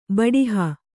♪ baḍiha